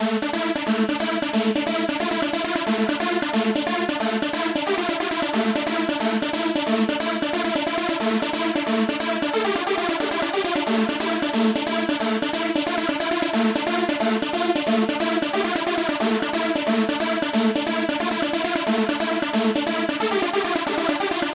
硬核旋律5
描述：硬核旋律
标签： 180 bpm Hardcore Loops Synth Loops 3.59 MB wav Key : A
声道立体声